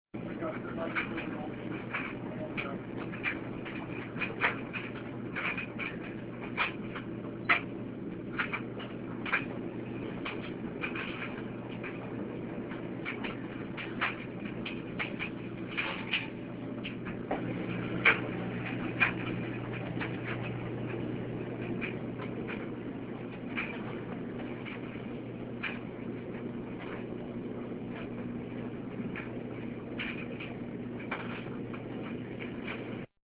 Laundry Room I was standing in the laundry room, where both washers and dryers were going. There is a faint humming in the background and the most prominent noise is the clicking of most likely something metal inside the dryer. Though the sound is not as clear in this recording as it was in person, I hoped to capture the rhythmic feel that I got standing inside the laundry room.
LaundryA.mp3